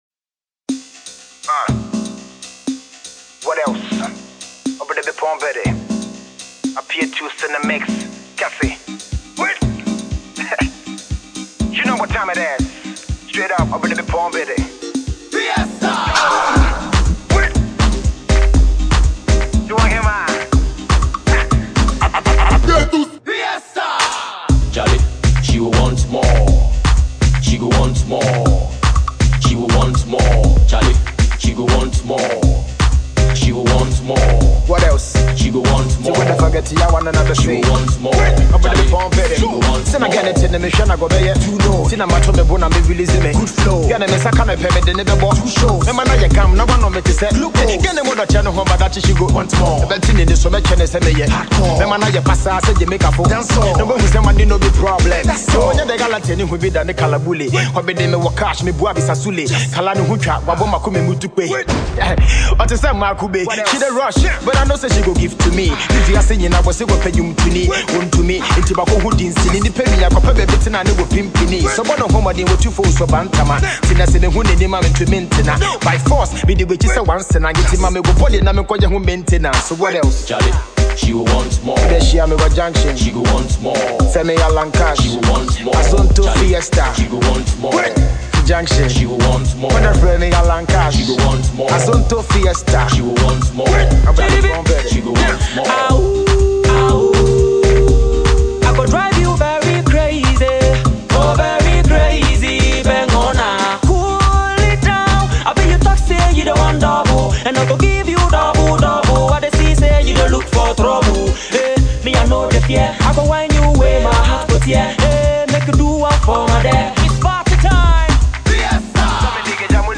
Azonto track
for the dance floor